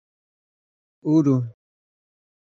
Pronunciación: